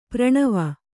♪ praṇava